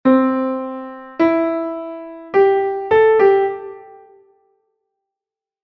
DO-MI-SOL-LA-SOL